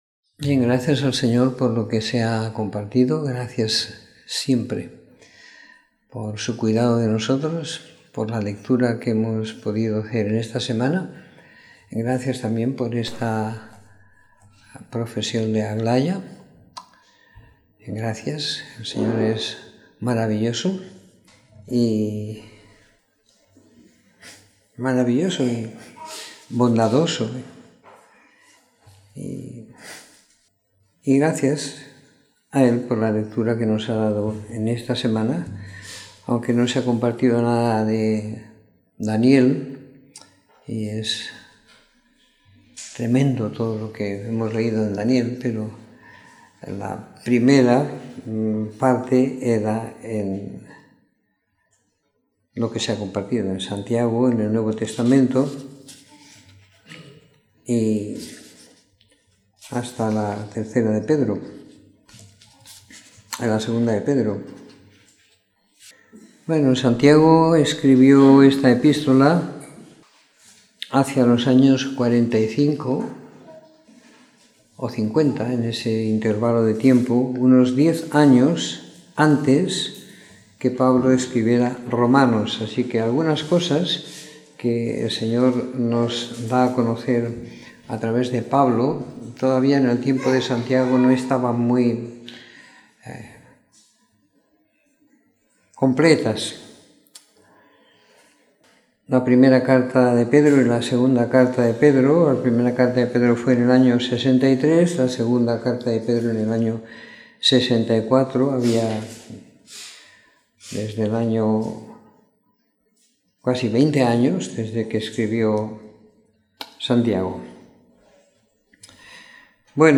Comentario en las epístolas de Santiago, 1ª Pedro y 2ª Pedro siguiendo la lectura programada para cada semana del año que tenemos en la congregación en Sant Pere de Ribes.